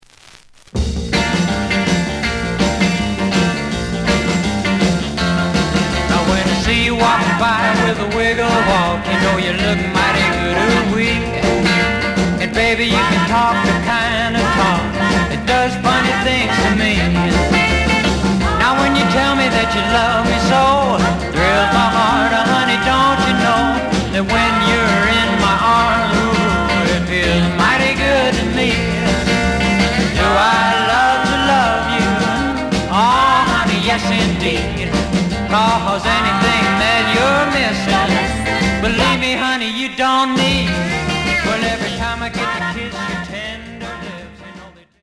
The audio sample is recorded from the actual item.
●Genre: Rhythm And Blues / Rock 'n' Roll
A side plays good.